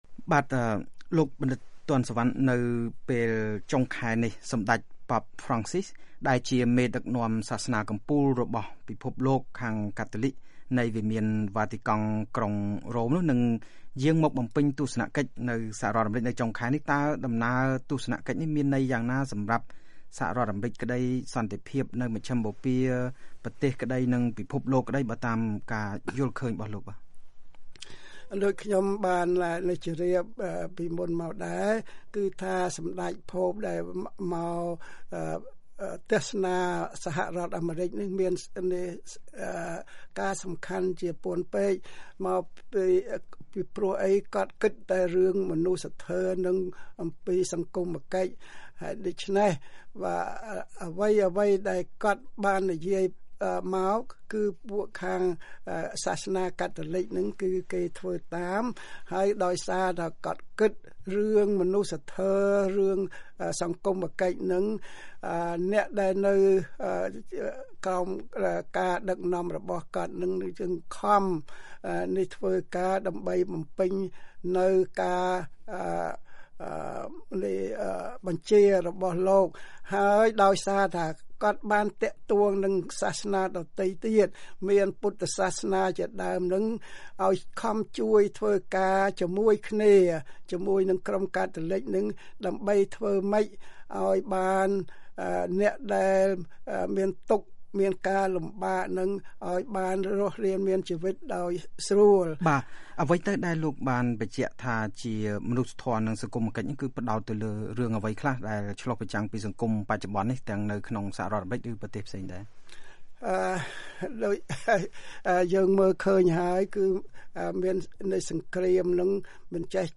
នៅក្នុងបន្ទប់ផ្សាយរបស់ VOA